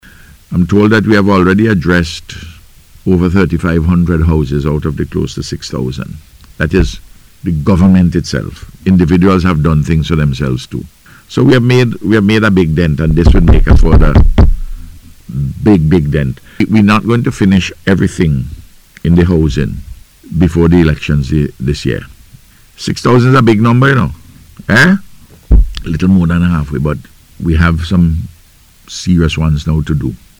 Speaking during NBC’s Face to Face programme aired on Wednesday, Prime Minister Gonsalves said other organizations and individuals have also been assisting in the rehabilitation of homes, amounting to more than half of the total number of homes already attended to.